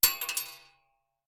Bullet Shell Sounds
pistol_metal_3.ogg